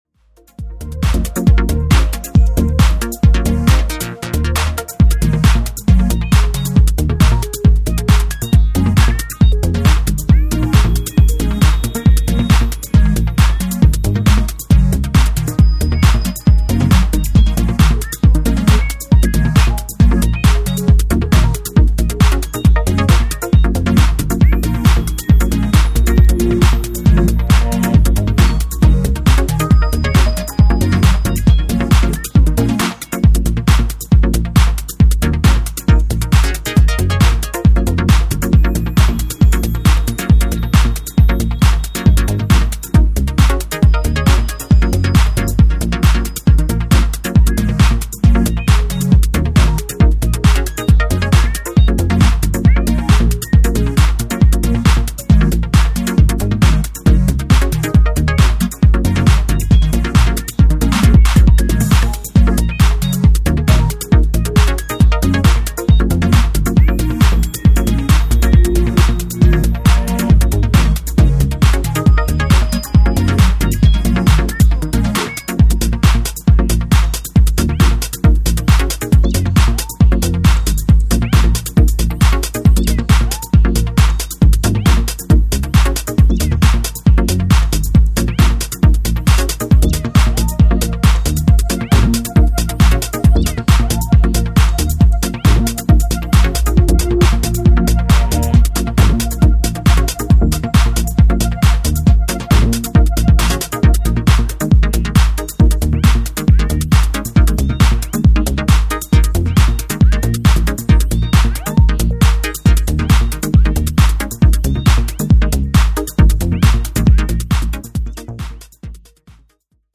deep driving transportation of the detroitish techno
hard-to-find dancefloor weapon